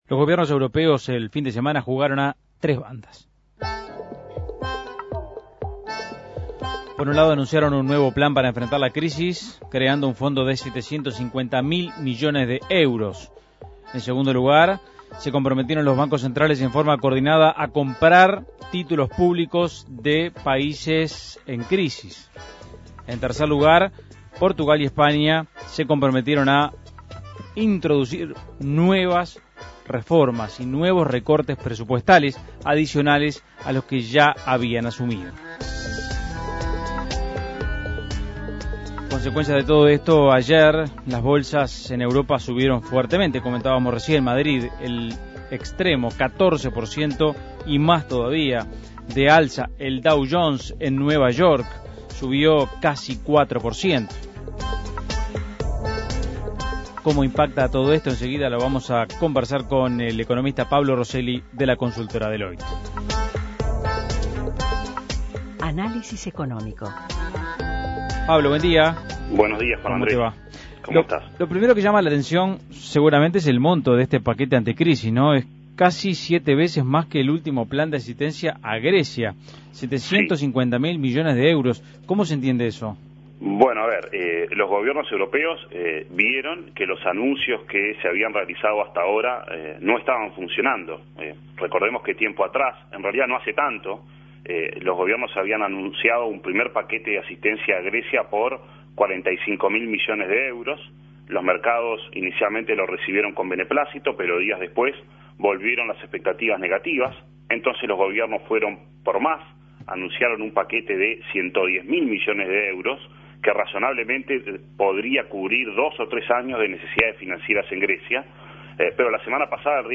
Análisis Económico Los gobiernos europeos anunciaron un "mega" plan anti crisis